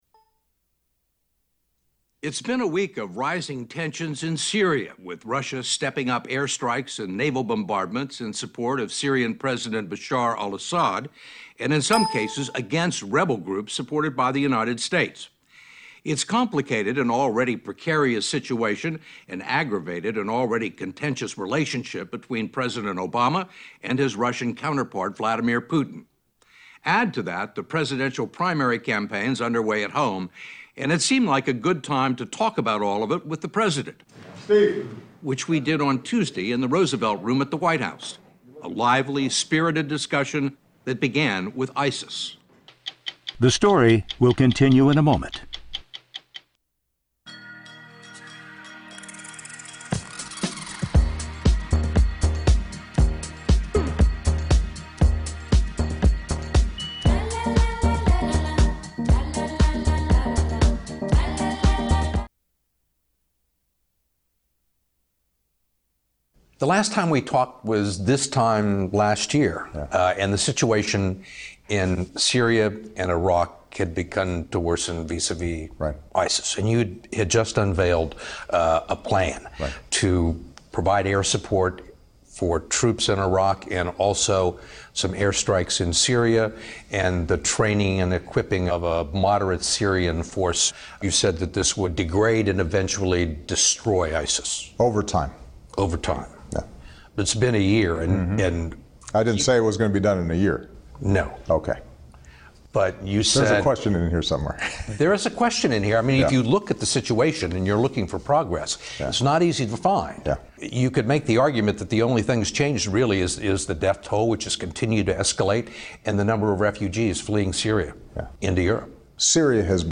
Interview of U.S. President Barack Obama on both domestic and international issues
President Barack Obama discusses various topics including, Russia's incursion into Syria, ISIS, the 2016 presidential race, Donald Trump, Hillary Clinton's emails and Joe Biden's possible run for president. Commercials included. On "60 Minutes."